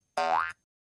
jump-1